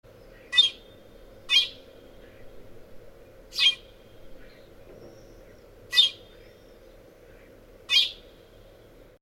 In this audio, each chirp is not just a single pitch, but begins with a higher-pitched, more strongly attacked sound that moves down.
I’ve moved it to much higher notes to more accurately imitate the actual frequencies.
And again, this time with the piccolo and sparrow recording combined…
piccolo-and-sparrow.mp3